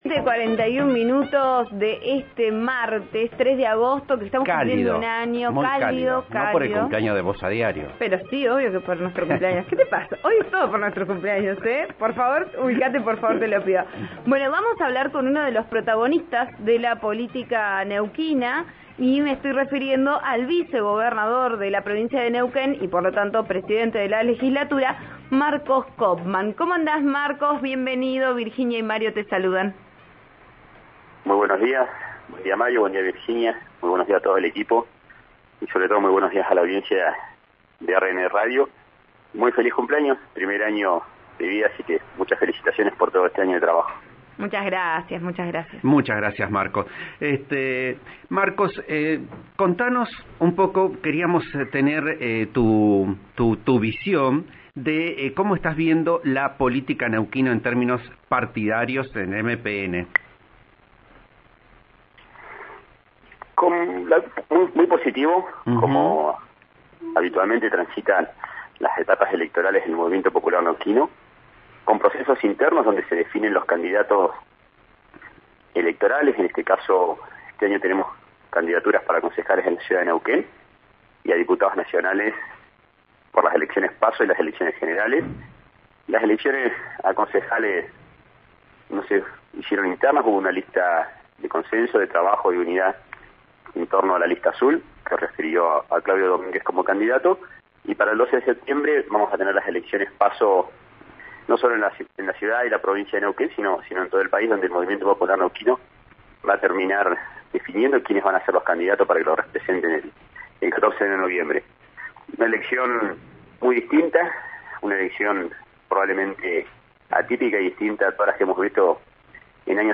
En diálogo con «Vos A Diario» (RN RADIO 89.3) adelantó los principales puntos en los que trabajará la Legislatura durante esta última mitad del año, entre los que mencionó la declaración de la emergencia hídrica , la reanudación de las modificaciones de la ley del compre neuquino e hizo especial hincapié en el impulso de un marco regulatorio de todos los servicios públicos.